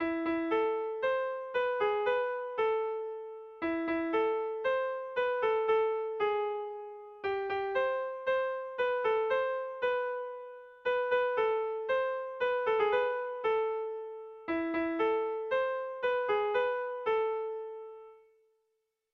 Sehaskakoa
A1A2BEA